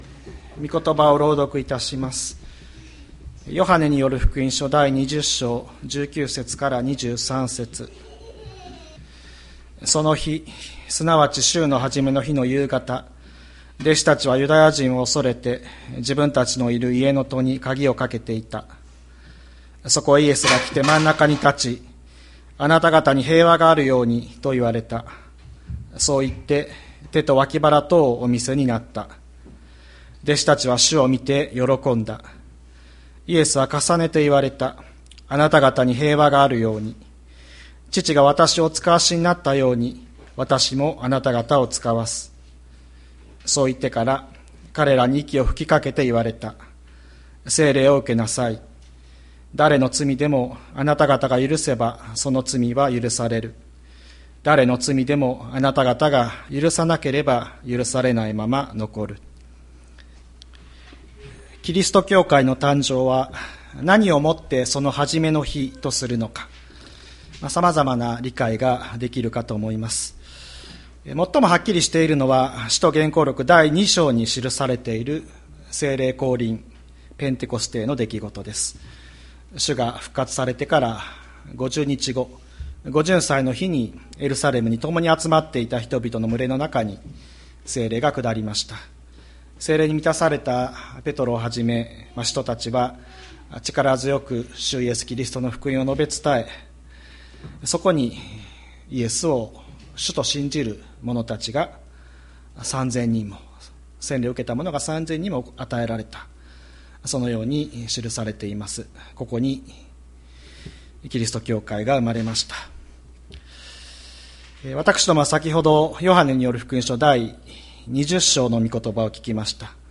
2023年05月28日朝の礼拝「新しい息吹に生かされ」吹田市千里山のキリスト教会
礼拝説教 日曜朝の礼拝